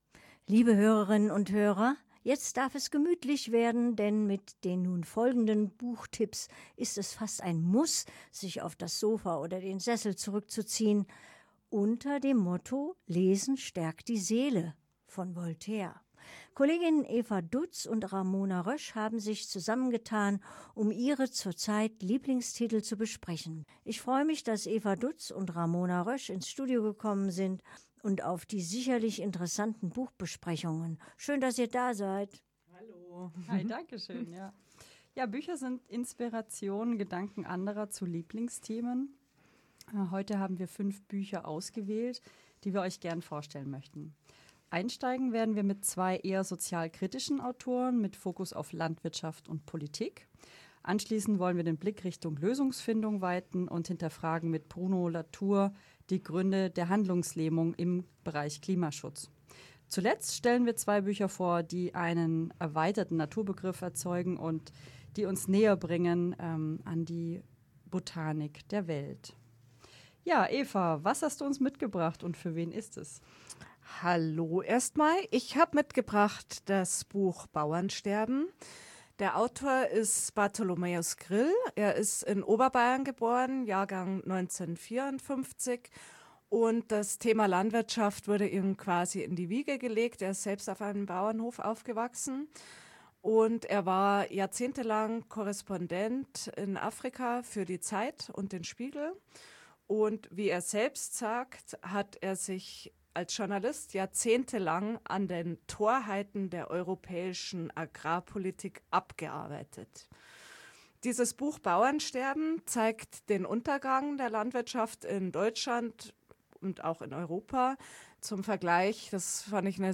Interview 3